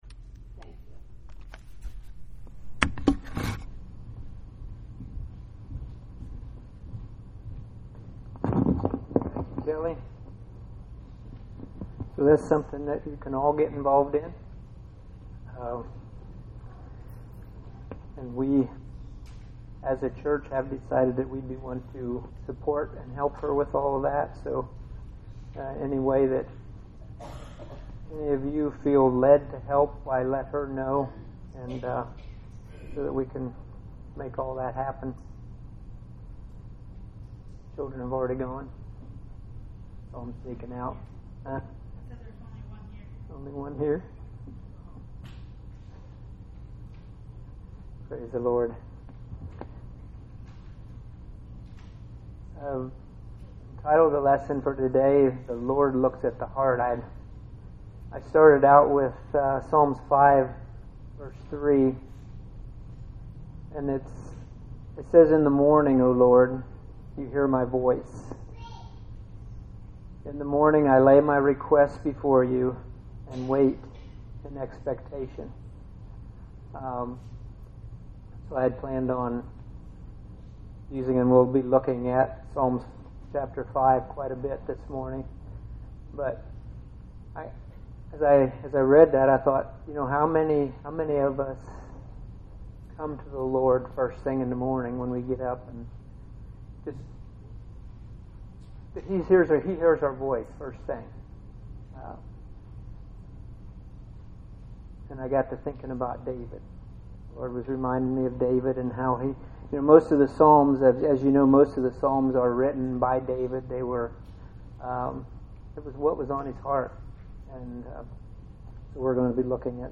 Bible Text: Psalm 5:3, Psalm 5:1-3, 1 Samuel 13:11-14, 1 Samuel 16:1-13, Deuteronomy 5:32-33, Deuteronomy 6:1-9, Psalm 37:3-7, Psalm 37:23-26, Psalm 37:39-40, Psalm 5:11-12, Psalm 91:1-16, Jeremiah 29:11-13 | Preacher